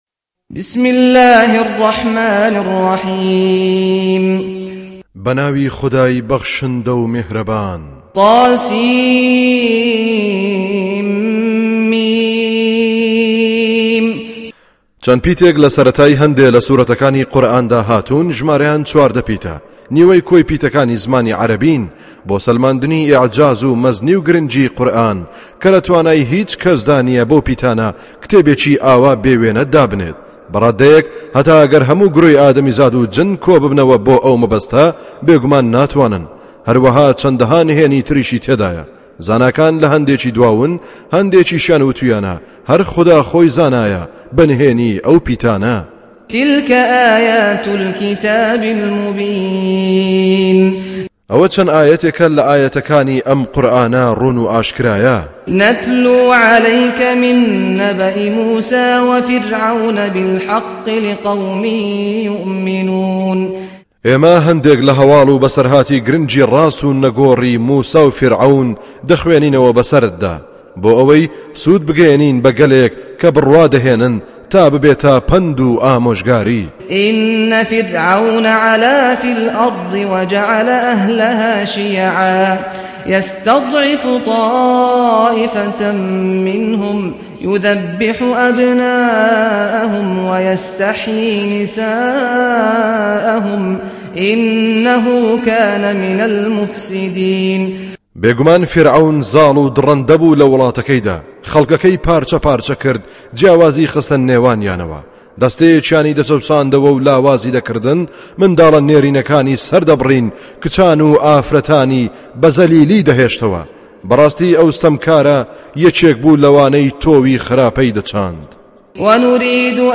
Recitation
With Qari SaadAl-Ghamdi